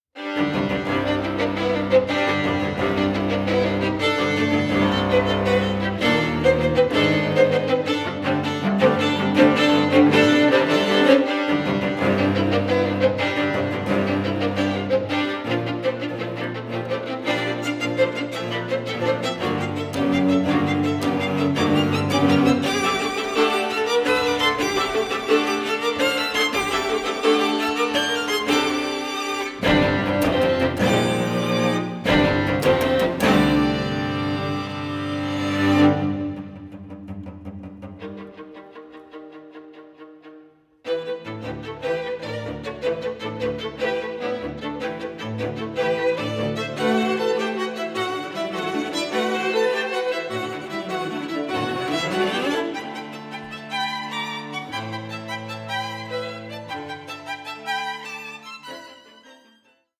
Allegramente rústico 4:06